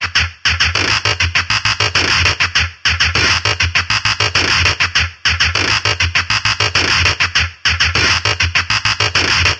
机械液压 " tom2
描述：机械
标签： 机械 工业 机械
声道立体声